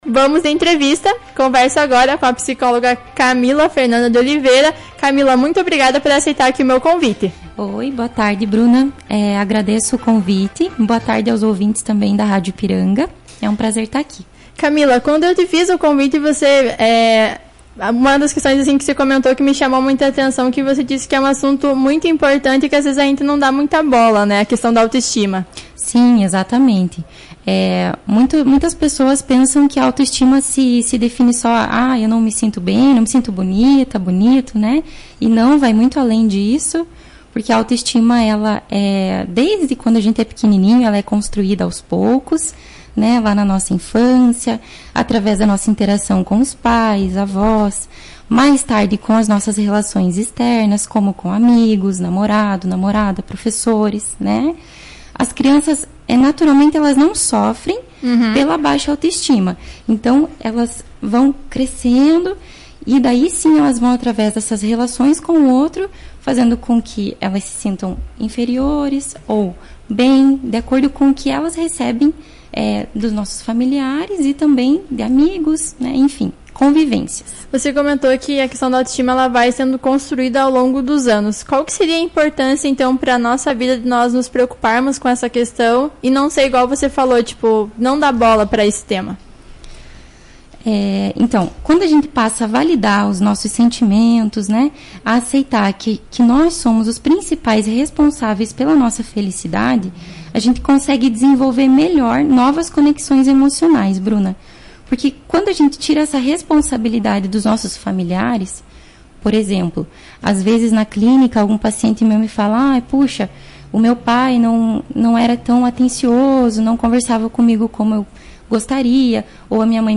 Acompanhe a entrevista na íntegra e aproveite para refletir sobre o assunto:
entrevista.mp3